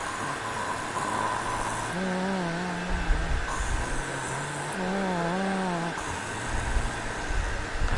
马来西亚纹理 " 马来西亚DT TE03 KLCentral Station
Tag: 中环站 纹理 吉隆坡 吉隆坡